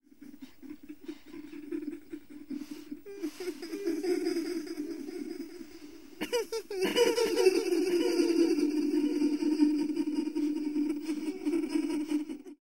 На этой странице собраны звуки момо — необычные и тревожные аудиоэффекты, которые подойдут для творческих проектов.
Звук смеха Момо вдалеке